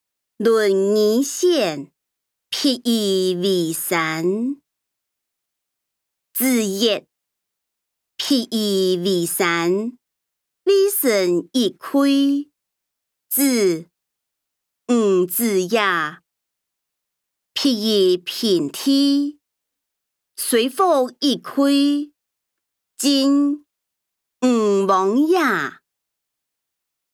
經學、論孟-論語選．譬如為山音檔(四縣腔)